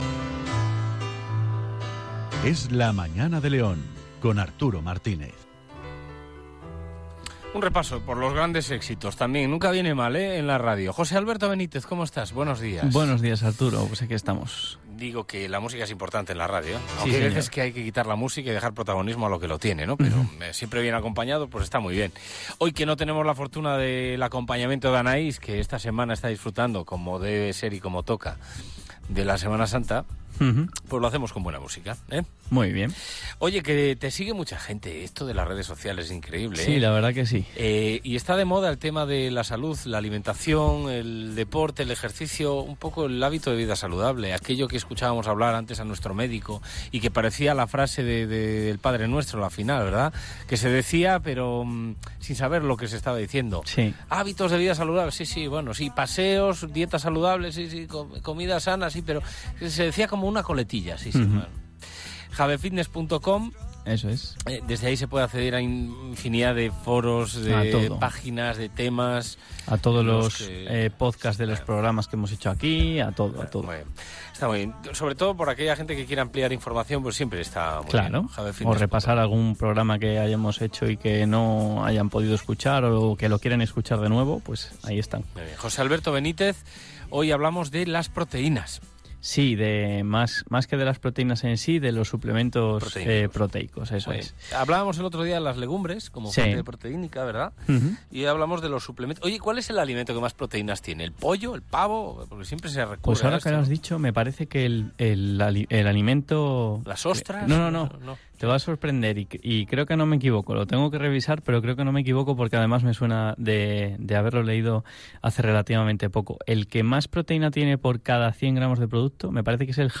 Hoy os traigo el sexagésimo tercer programa de la sección que comenzamos en la radio local hace un tiempo y que hemos denominado Es Saludable.